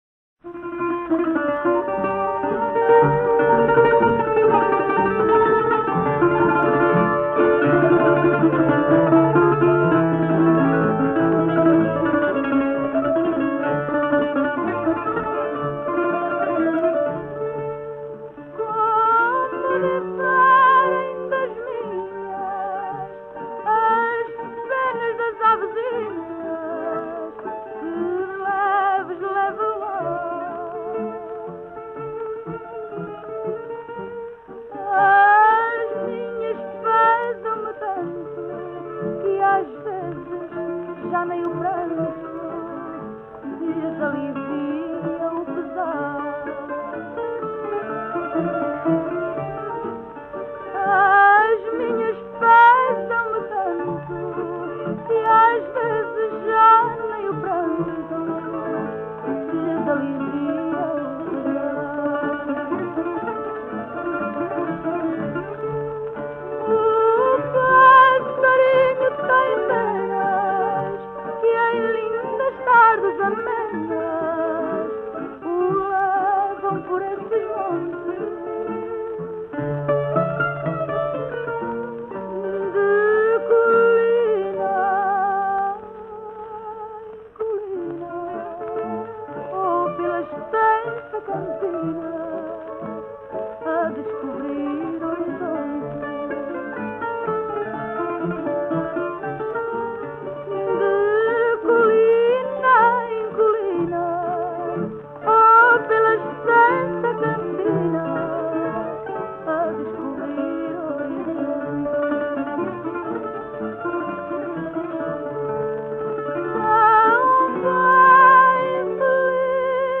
chant.
from → Adorable, Fado, Fado castiço / Fado traditionnel